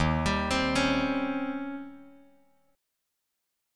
D#13 chord